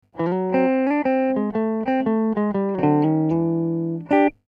Акустический звук - Усилитель - "Regent30-Mod", гитарный динамик - "Celestion Greenback", 1 микрофон "Shure SM 57", сигнал с динамика - левый канал, сигнал с предварительного усилителя "Regent30-Mod" - правый канал (линия), микшер, звуковая карта компьютера.
Датчики : bridge - Dimarzio Fast Track2, middle - Dimarzio FS-1, neck - Dimarzio DP404
Акустический звук, датчик NECK
Момент атаки ещё более яркий, чем с ольхой, затухание струн имеет более уверенный характер.
Звук этой гитары мягким назвать нельзя, он больше подходит под классическую трактовку "Stratocaster".